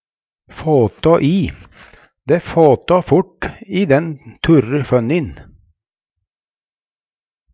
DIALEKTORD PÅ NORMERT NORSK fåtå i ta fyr, byrje å brenne Eksempel på bruk Det fåtå fort i den turre fønnin (det turre graset) Hør på dette ordet Ordklasse: Uttrykk Kategori: Uttrykk Karakteristikk Attende til søk